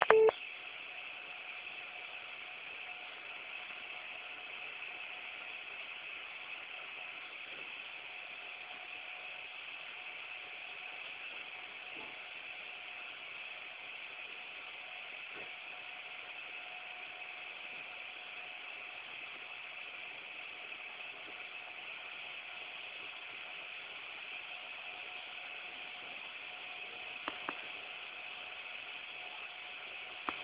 Chłodzenie karty miedzianym radiatorem z wentylatorem.
evga7900gs.wav